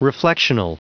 Prononciation du mot reflectional en anglais (fichier audio)
Prononciation du mot : reflectional